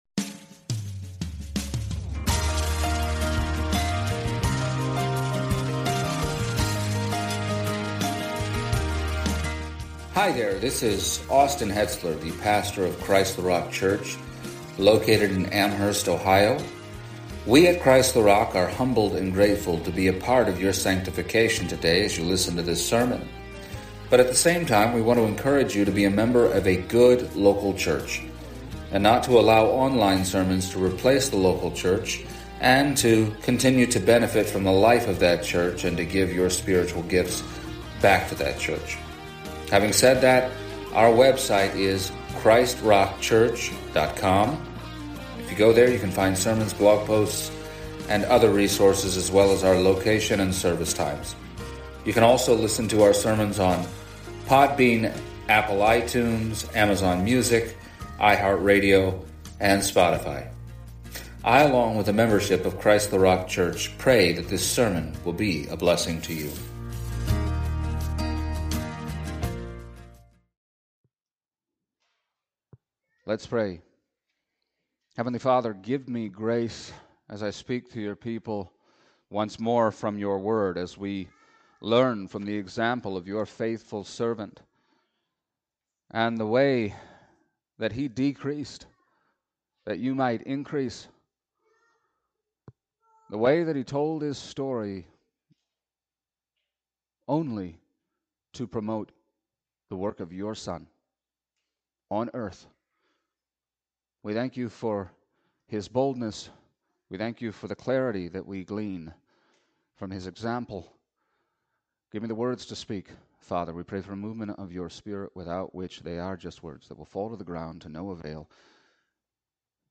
Passage: Acts 26:1-32 Service Type: Sunday Morning